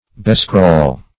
Bescrawl \Be*scrawl"\